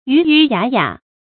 魚魚雅雅 注音： ㄧㄩˊ ㄧㄩˊ ㄧㄚˇ ㄧㄚˇ 讀音讀法： 意思解釋： 形容車駕前行威儀整肅的樣子。